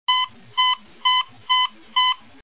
alarma
alarma.mp3